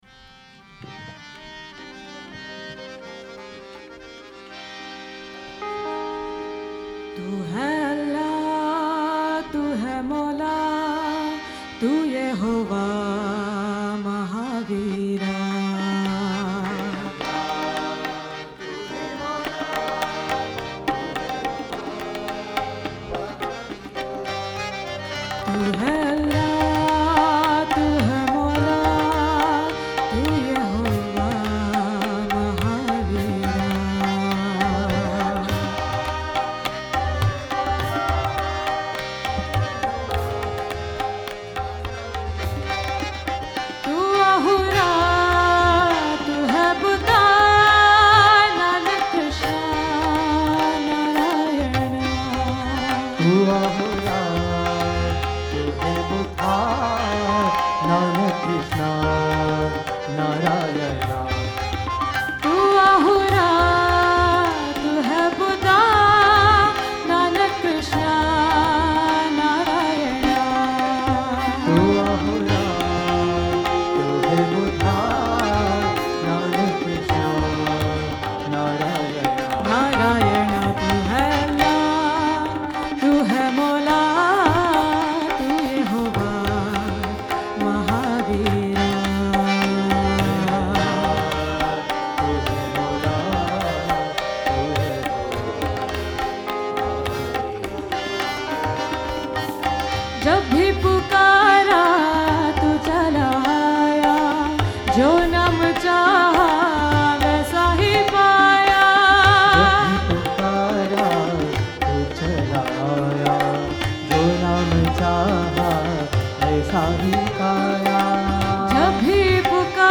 1. Devotional Songs
Mishra Keeravani 8 Beat  Men - 3 Pancham  Women - 7 Pancham
Mishra Keeravani
8 Beat / Keherwa / Adi